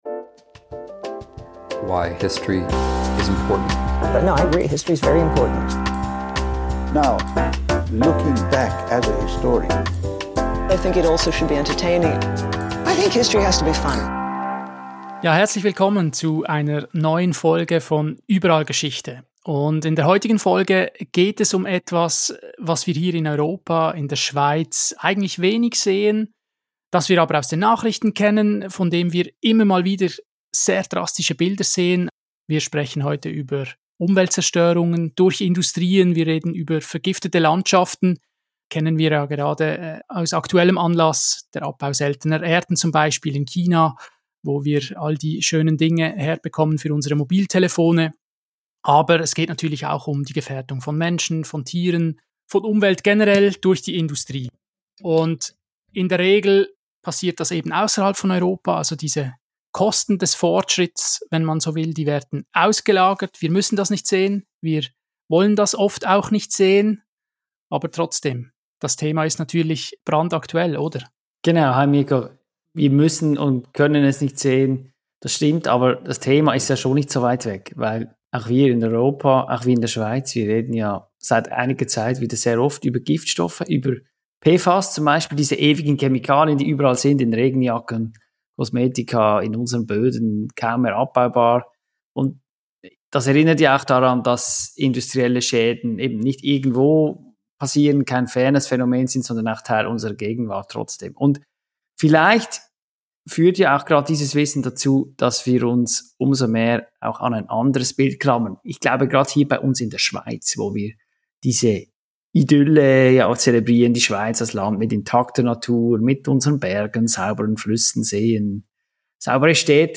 Ein Gespräch über die dunklen Spuren der Moderne und über die blinden Flecken, die sie hinterlassen hat.